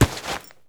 foley_combat_fight_grab_throw_03.wav